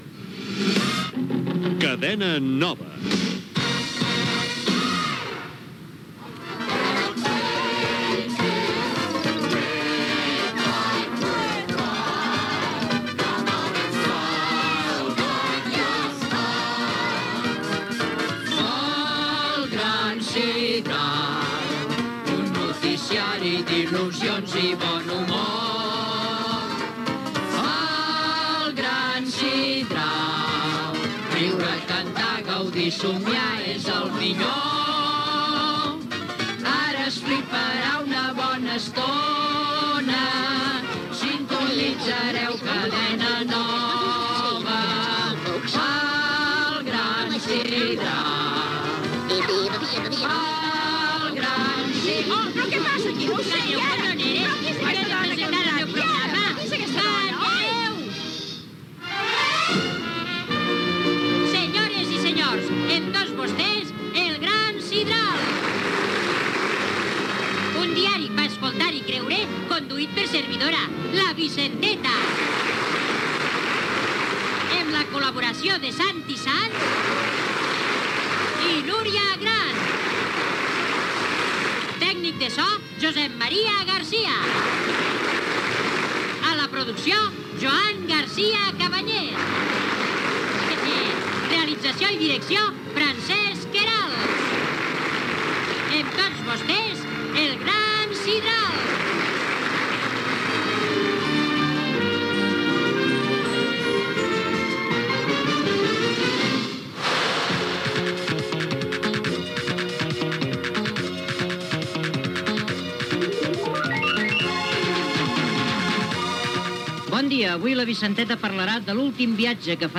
Idicatiu de l'emissora, sintonia canatada del programa, equip, sumari, diàleg inicial, acudit, comentari sobre la cantant Dolly Parton, concurs
Entreteniment